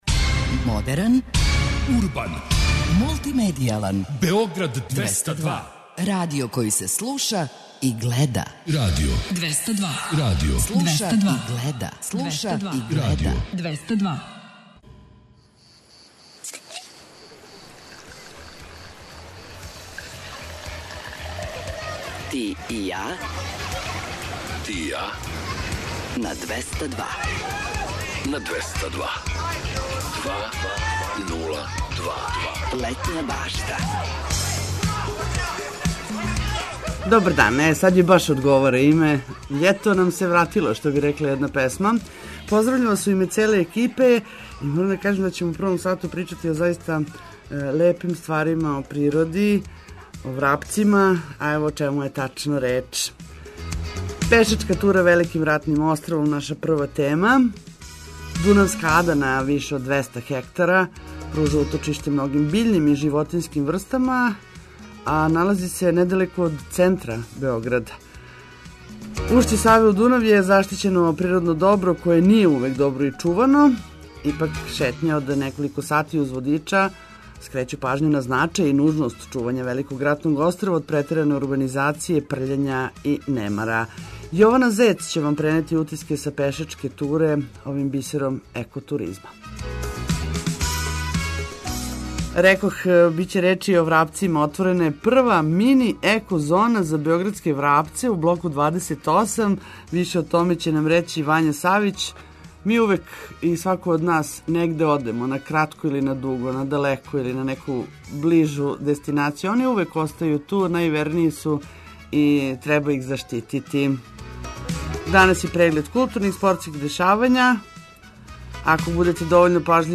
За боље организовање дана ту су вести и друге важне информације. Зачин пријатном дану биће ведра музика, лепе вести и водич кроз текуће манифестације у Србији, а уколико нас будете пажљиво слушали, можете да добијете карте за HILLS UP фестивал на Златибору!